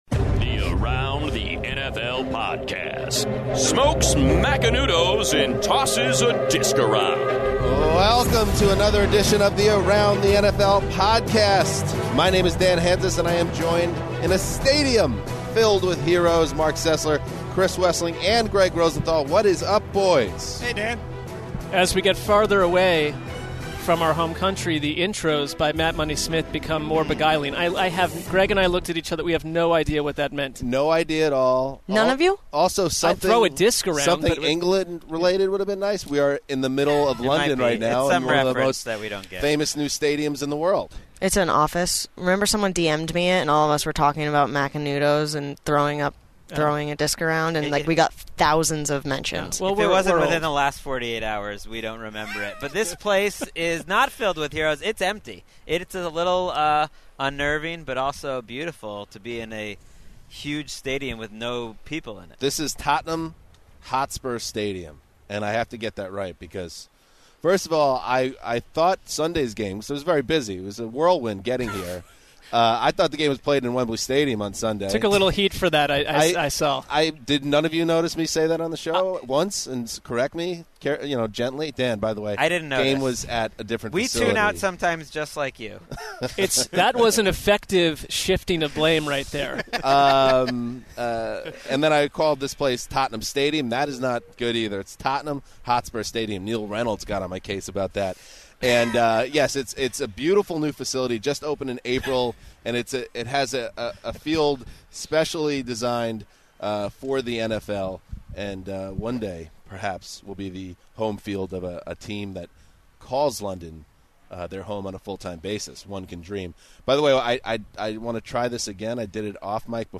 Forgive any technical difficulties!